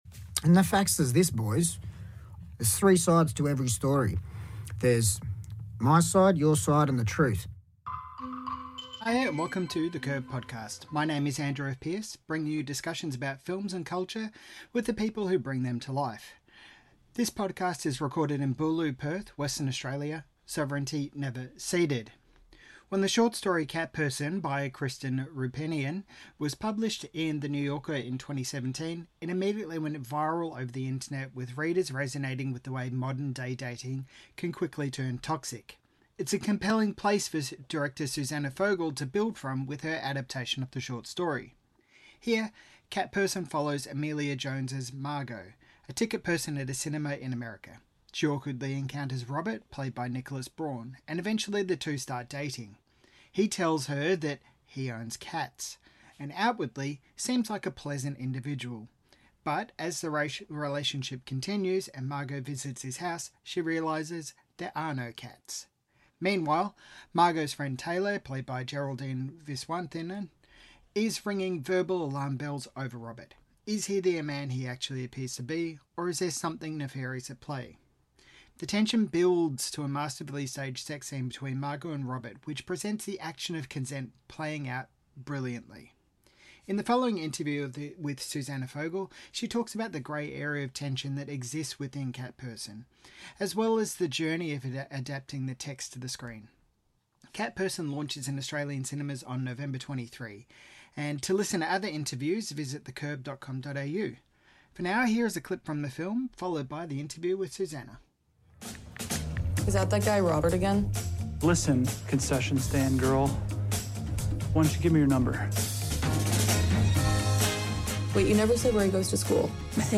Cat Person Director Susanna Fogel Talks About Presenting the Grey Area of Modern Dating on Screen in This Interview - The Curb